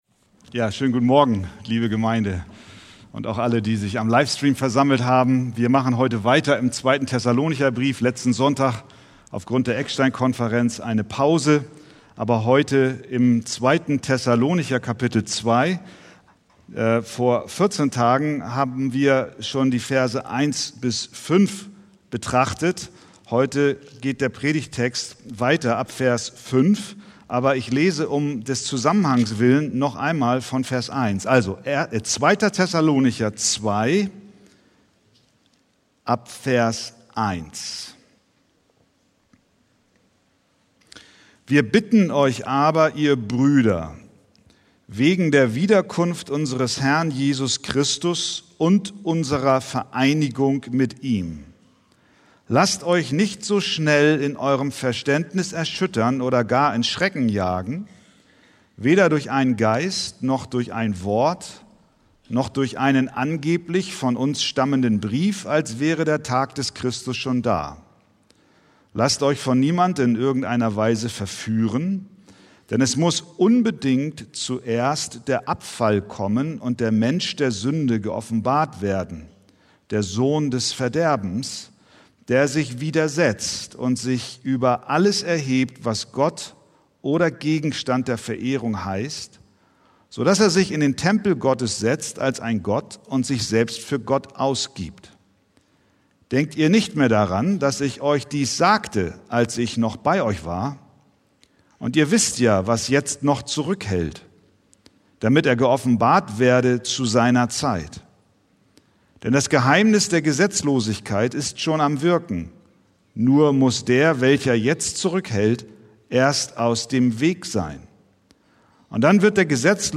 Predigttext: 2. Thessalonicher 2,5-12